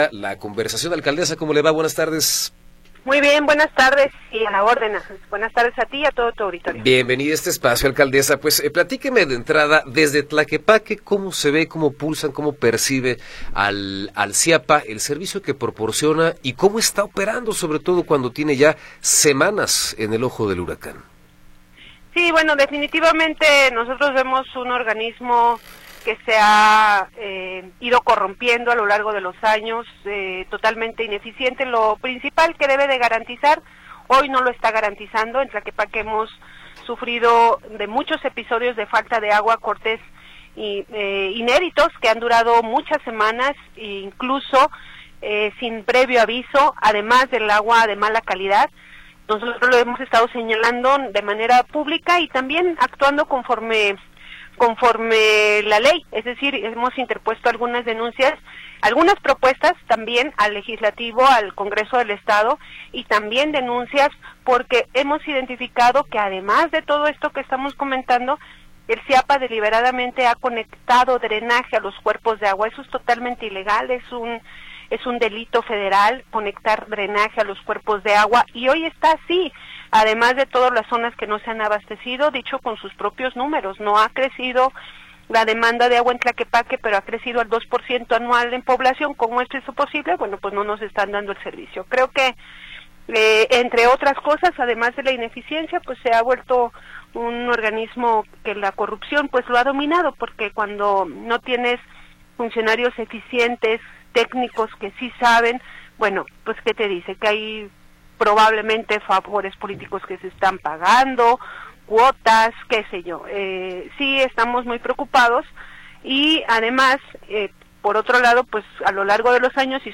Entrevista con Laura Imelda Pérez Segura